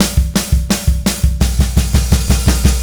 Indie Pop Beat 01 Fill.wav